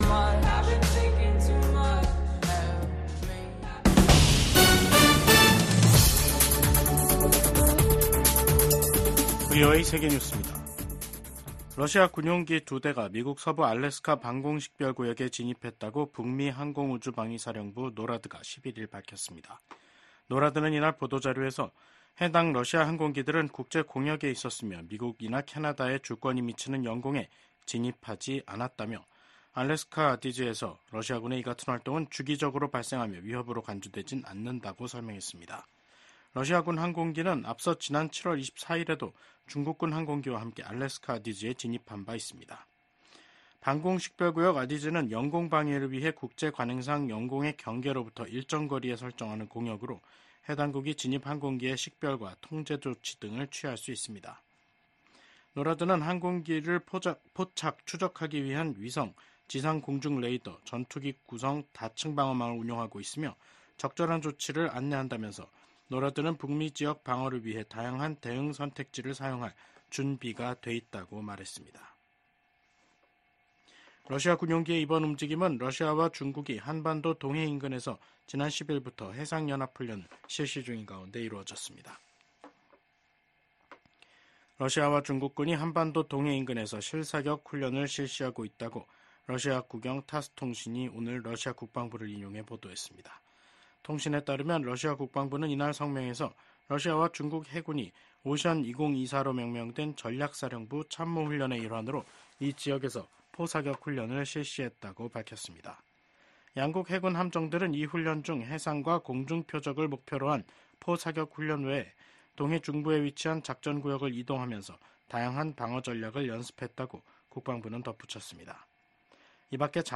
VOA 한국어 간판 뉴스 프로그램 '뉴스 투데이', 2024년 9월 12일 3부 방송입니다. 북한이 70여일 만에 또 다시 단거리 탄도미사일 도발에 나섰습니다. 미국 정부가 핵무기를 기하급수적으로 늘리겠다는 북한 김정은 국무위원장의 발언과 관련해 북한의 불법 무기 프로그램이 국제 평화를 위협한다고 지적했습니다. 미국이 북러 간 무기 거래를 강력히 규탄하며 관련 행위를 즉각 중단하라고 촉구했습니다.